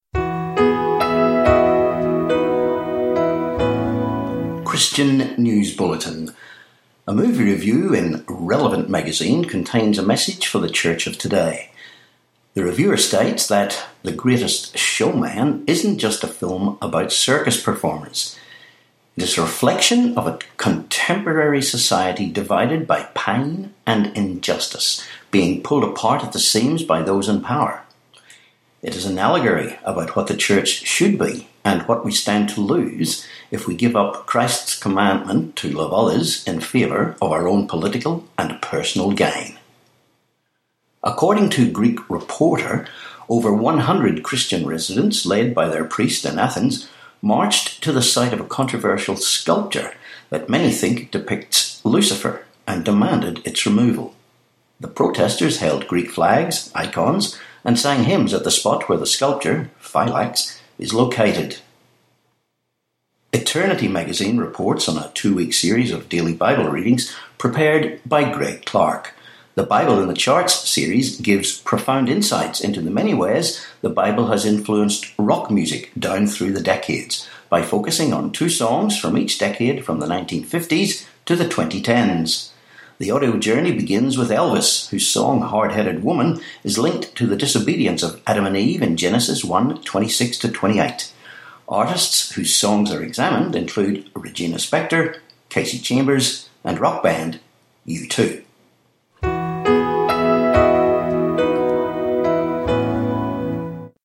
Christian News Bulletin, podcast